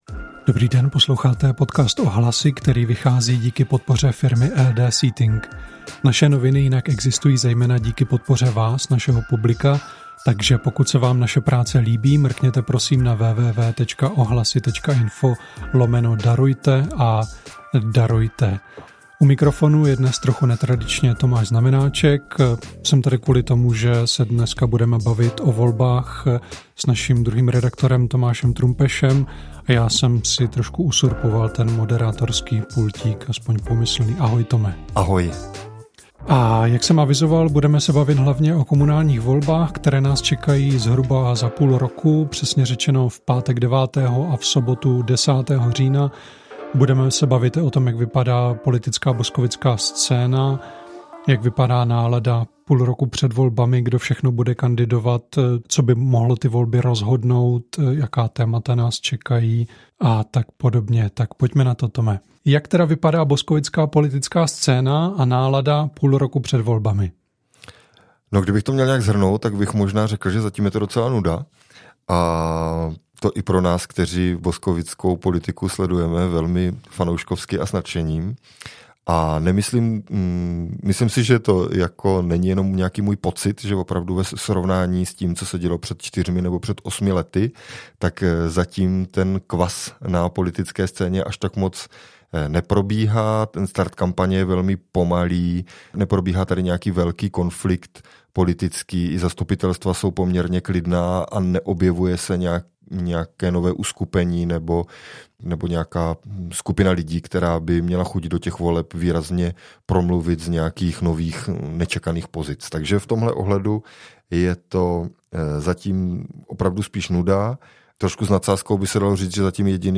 Půl roku do voleb – debata o politické situaci v Boskovicích